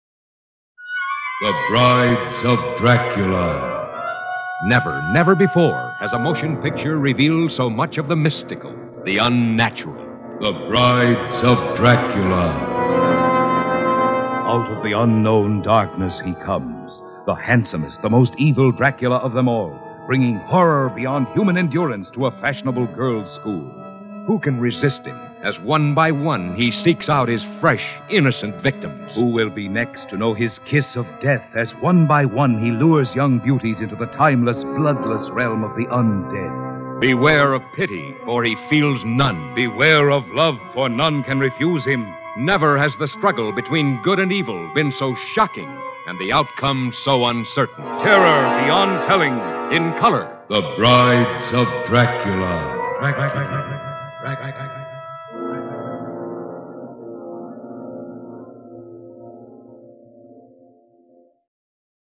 Radio Spots
The radio spots presented here are atmospheric, and capture the thrills in store for the theatergoer.